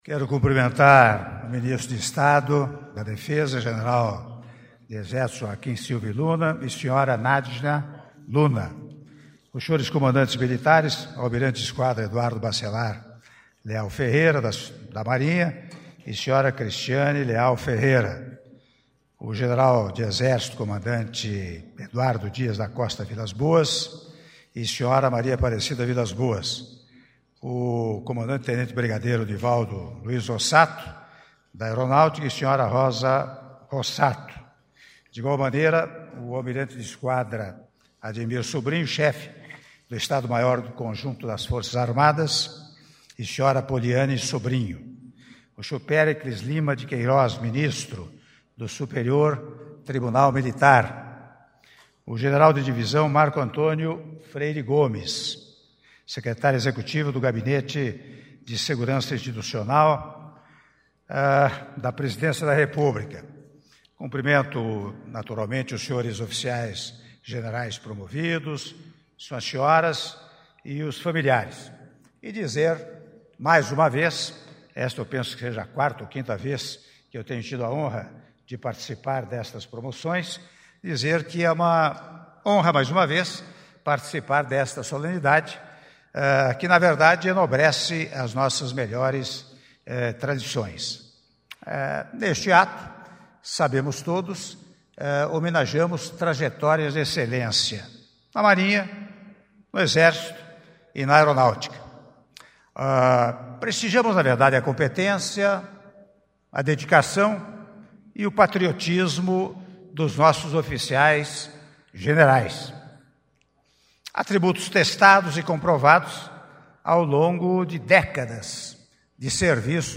Áudio do discurso do Presidente da República, Michel Temer, durante Cerimônia de apresentação dos Oficiais Generais promovidos - Palácio do Planalto (08min03s)